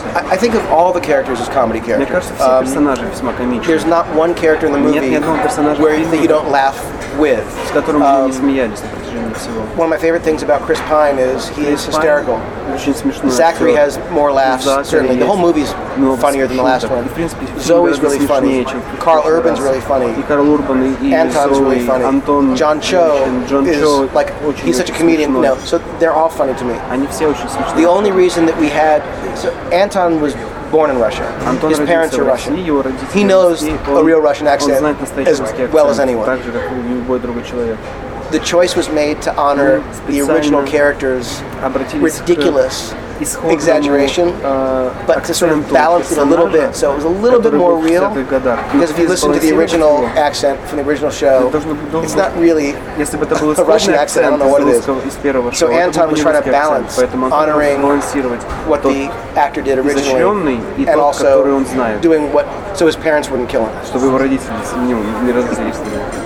Режиссер фантастического фильма "Стартрек: Возмездие (Звездный путь 2)" Джей Джей Абрамс в эксклюзивном интервью ответил на вопросы портала "Новости Кино".
Я сидел с ним за столиком в ресторане , а рядом сидел переводчик:) Мне он был не нужен, я просто хотел, чтобы синхронный превод был сразу, чтобы не накладывать его потом при монтаже:) Но вышлоне очень удачно, так как было очень шумно вокруг.
Просто иногда голос Абрамса почему-то заглушает голос переводчика, из-за чего трудновато слушать) Спасибо за нестандартное интервью и за то, что задали мой вопрос про Алькатрас)